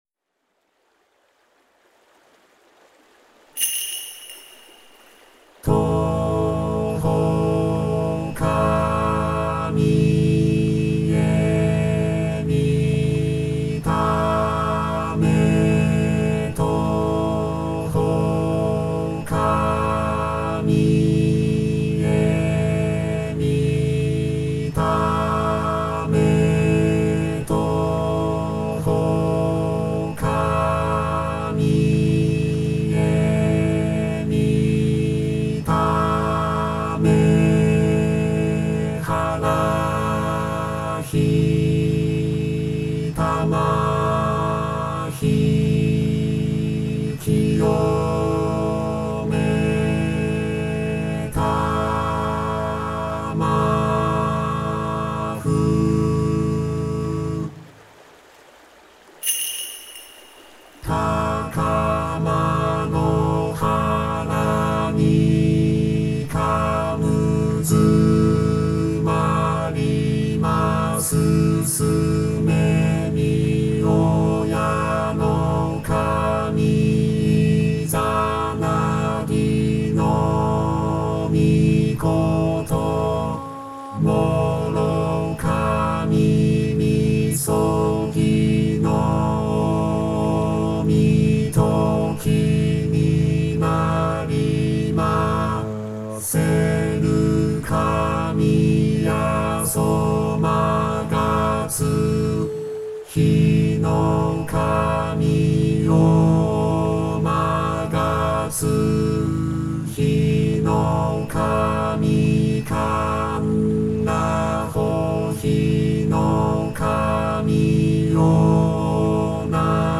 特別version祓いボーカロイド 構文記入用紙・画像データ 【特別version 】 祓いボーカロイド ダウンロードはこちら 【弊社オリジナル紙置き用】 構文記入用紙・画像データ ダウンロードはこちら プライバシーポリシー 特定商取引に関する記述 お問い合わせ © 言霊学マスター講座 オンラインスクール
toku_harai_vocaloid.mp3